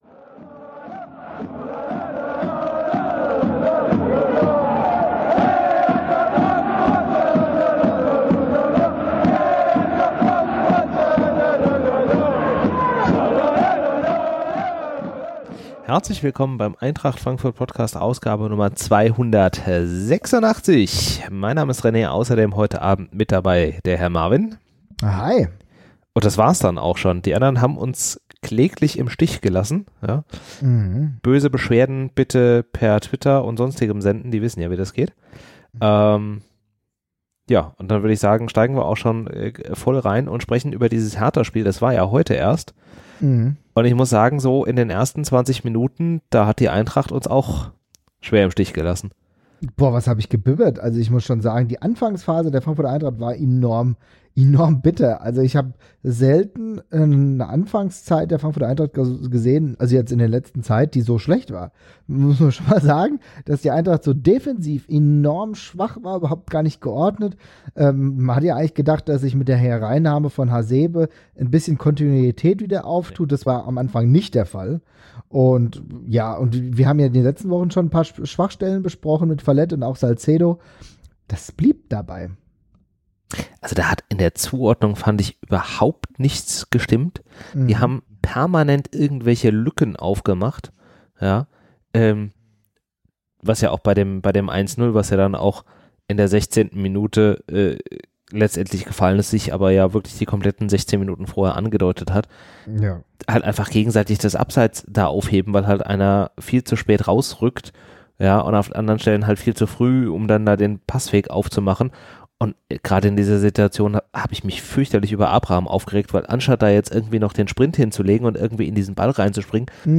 Wir nehmen diesmal kurz nach dem Spiel auf, weil es einfach am besten gepasst hat. Mit 22 Punkten nach 14 Spieltagen haben wir das Soll erfüllt und sogar 3 Punkte aus Berlin entführt.